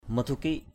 /mə-tʱu-ki:ʔ/ (t.) bệnh hoạn = illness